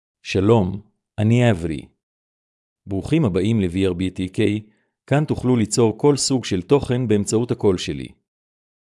AvriMale Hebrew AI voice
Avri is a male AI voice for Hebrew (Israel).
Voice: AvriGender: MaleLanguage: Hebrew (Israel)ID: avri-he-il
Voice sample
Listen to Avri's male Hebrew voice.